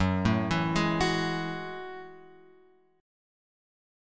F# Minor 9th